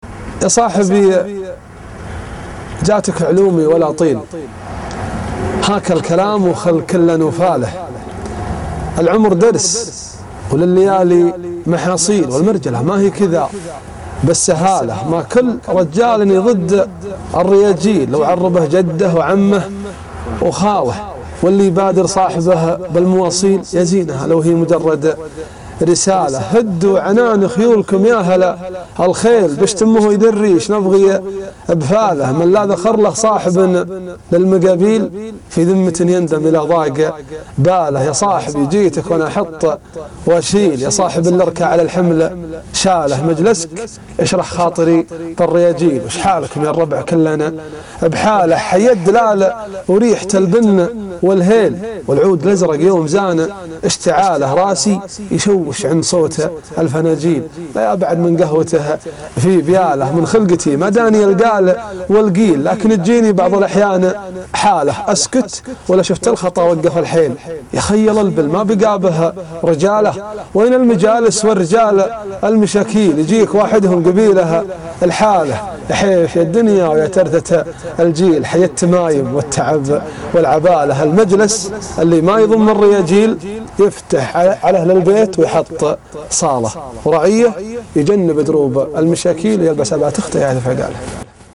ياصاحبي جاتك علومي ( في قناة الصحراء )   30 مارس 2012